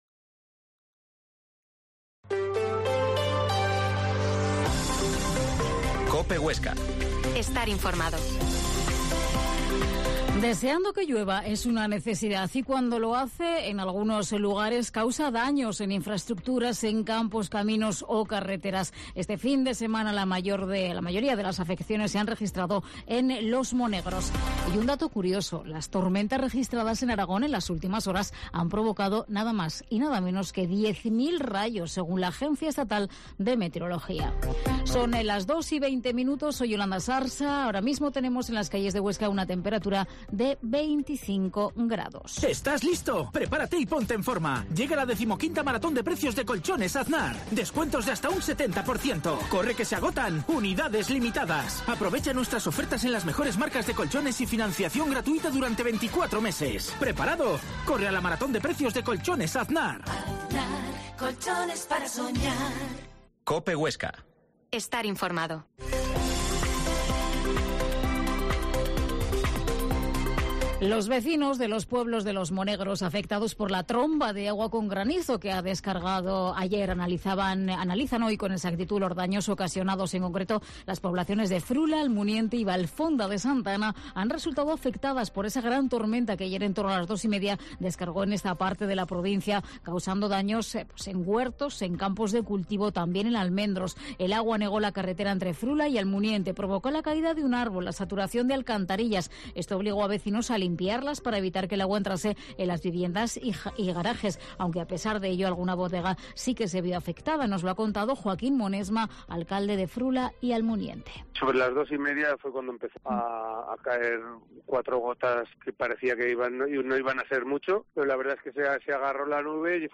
Informativo local